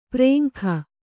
♪ prēŋkha